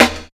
108 SNR 2 -L.wav